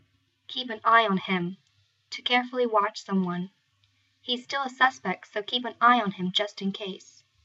また、 eye は keep a close eye on のように、形容詞とともに使われる場合もあります、 英語ネイティブによる発音は以下のリンクをクリックしてください。